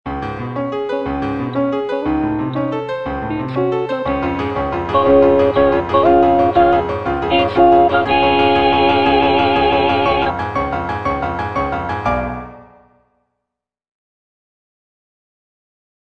G. BIZET - CHOIRS FROM "CARMEN" En route, en route (soprano I) (Emphasised voice and other voices) Ads stop: auto-stop